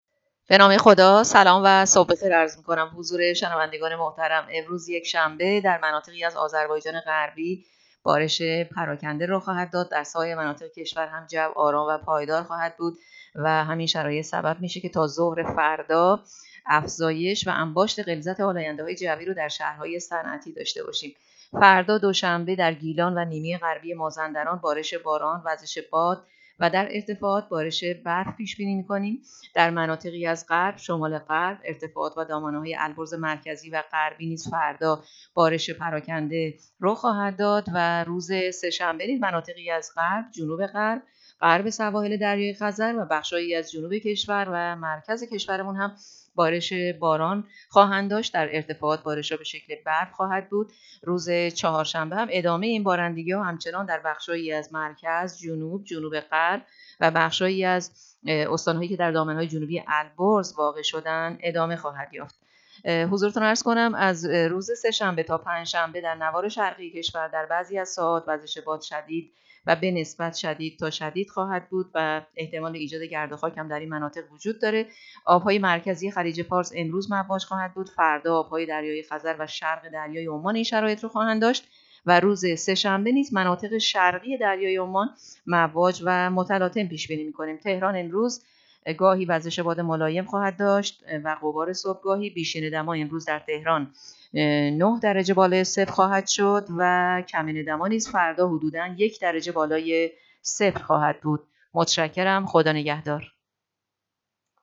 گزارش آخرین وضعیت جوی کشور را از رادیو اینترنتی پایگاه خبری وزارت راه و شهرسازی بشنوید.
گزارش رادیو اینترنتی پایگاه‌ خبری از آخرین وضعیت آب‌وهوای ۱۶ دی؛